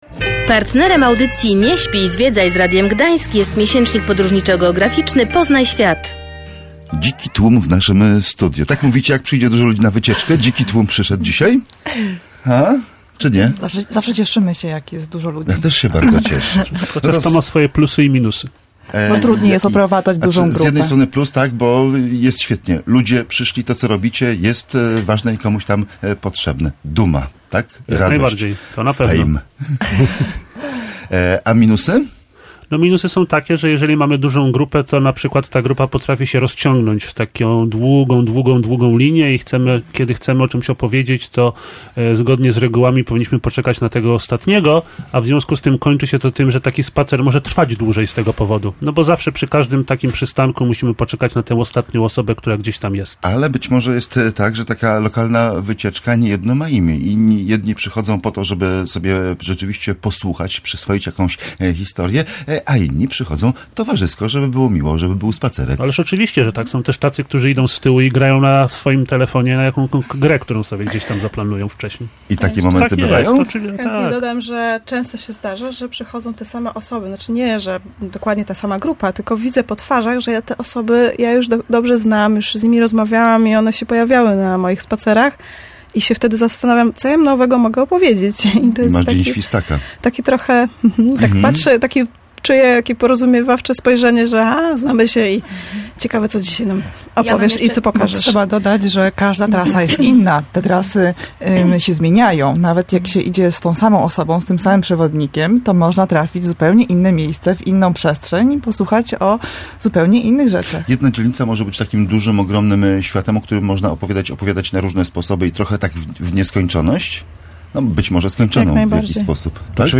Posłuchaj audycji:/audio/dok2/przwewodnicy.mp3